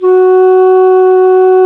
Index of /90_sSampleCDs/Roland LCDP04 Orchestral Winds/FLT_Alto Flute/FLT_A.Flt nv 3
FLT ALTO F07.wav